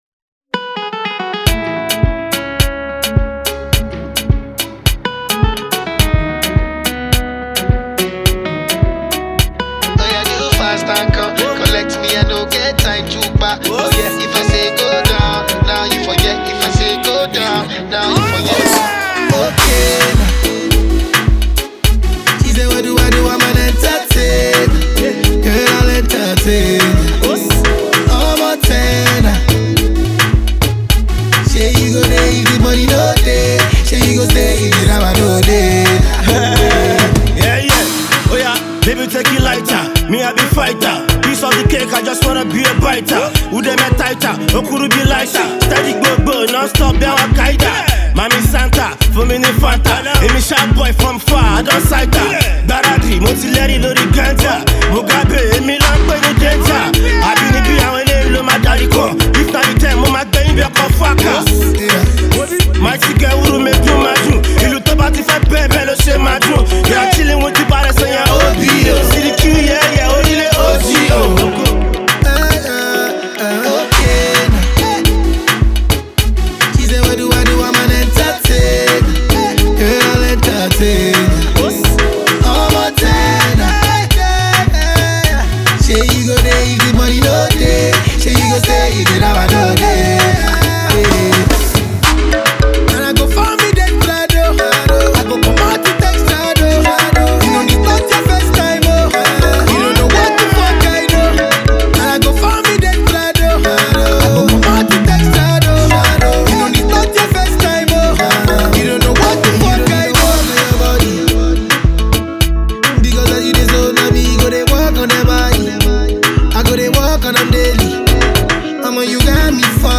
Nigerian indigenous rapper